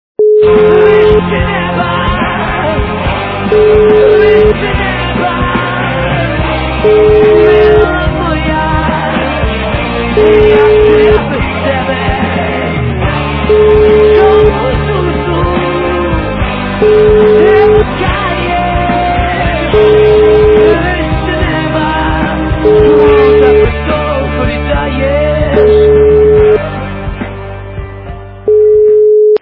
украинская эстрада